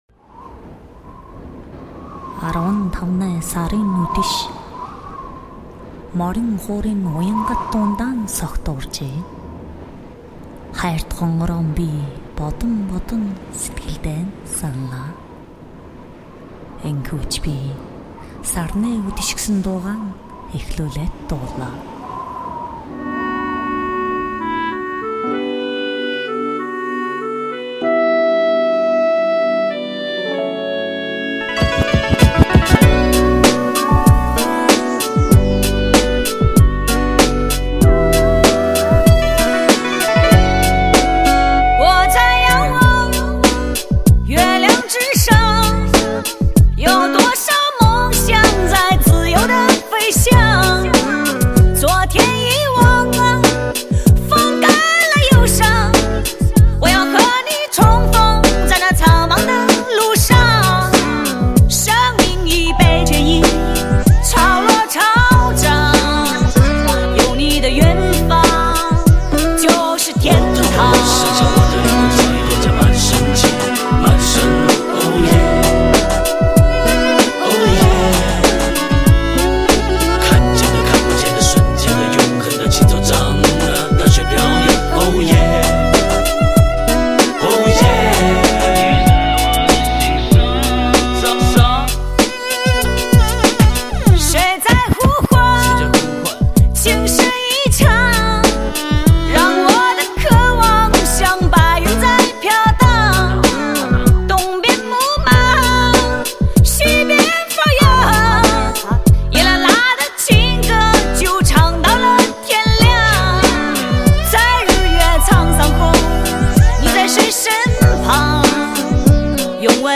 高亢清亮的旋律在草原大漠上飘扬，节奏化的说唱脱缰野马般翻滚，经典的民歌元素与电子音乐完美和谐的结合，给人以遐想和震撼……